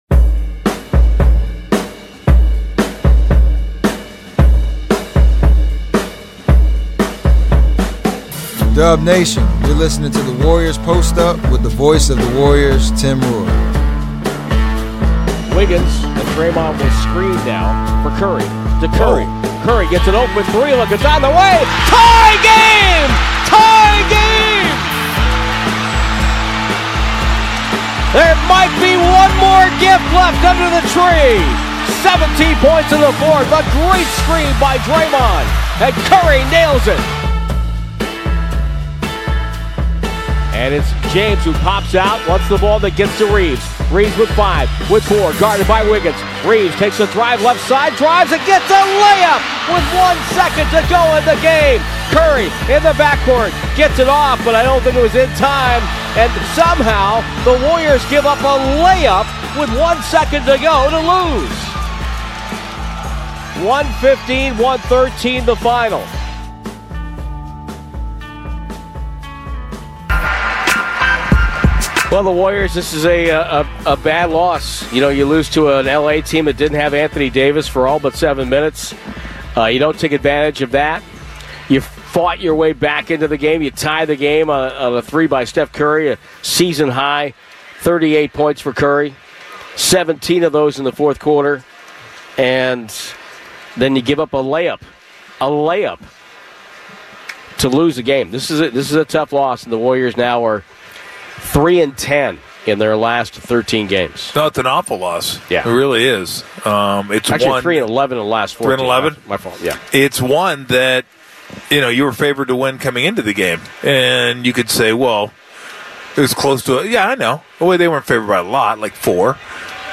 Official Golden State Warriors shows with interviews and analysis direct from the team, focusing on topics from in and around the NBA